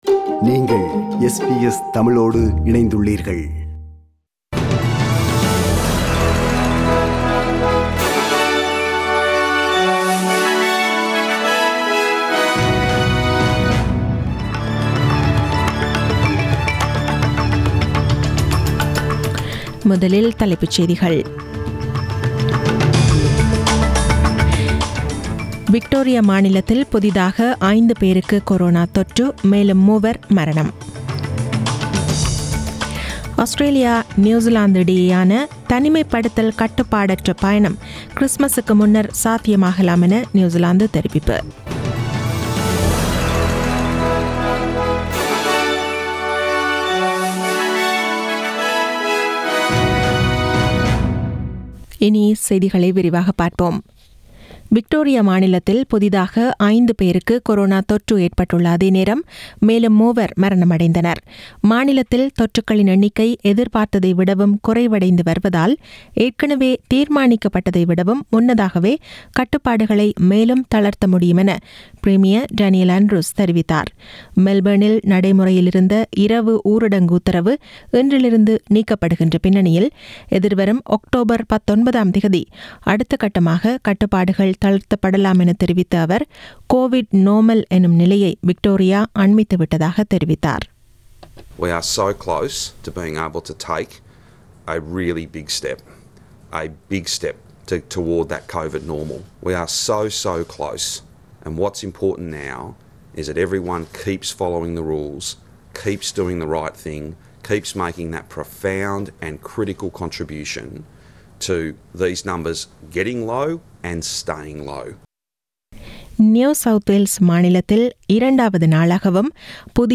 The news bulletin was aired on 28 September 2020 (Monday) at 8pm